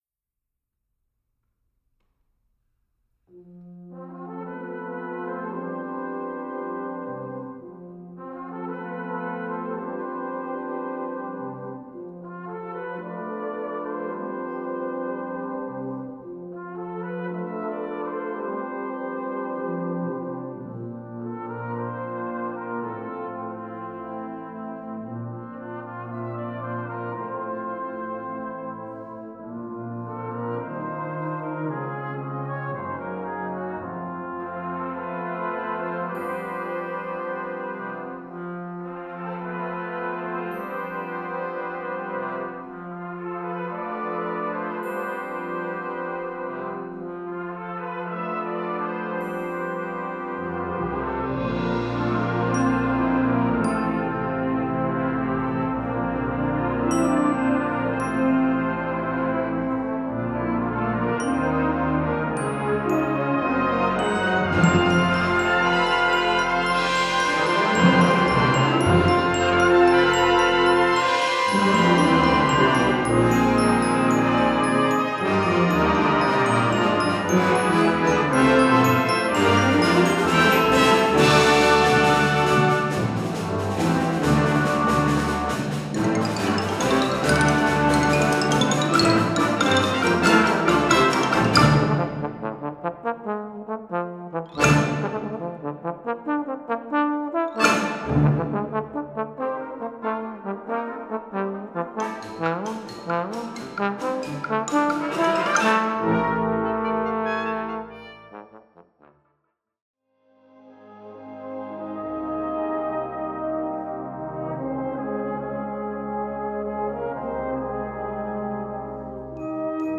The first part begins very modestly.
The second part is in a lyrical mood.
Bass Trombone solo
Timpani
Harp
Celesta